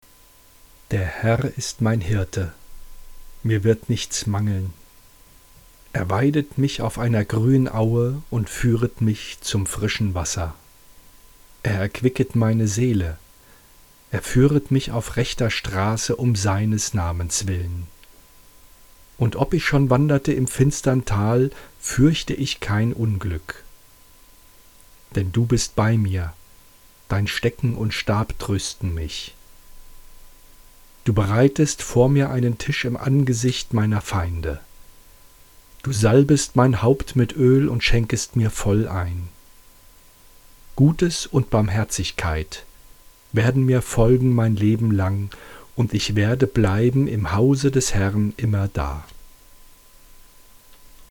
HIER als mp3 Datei für Sie gesprochen habe: